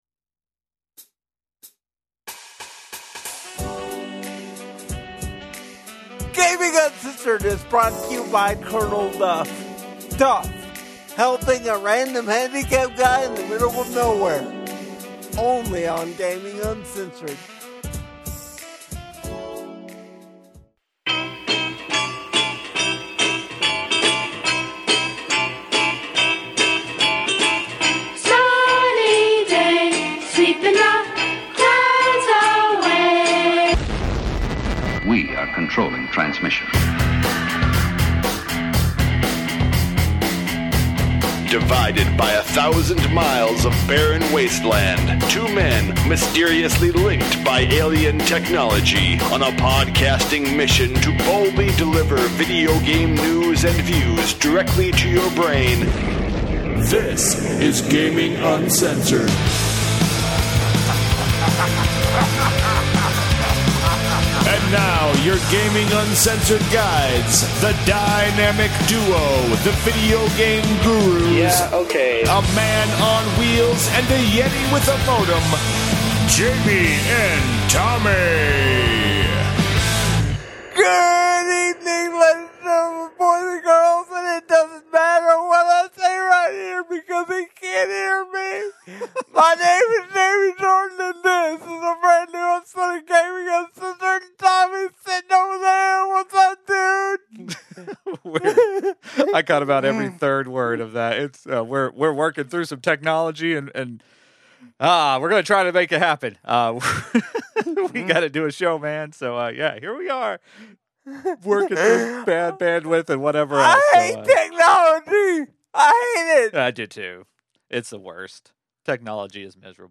NOTE: we had really bad internet, so apologies for walking all over each other.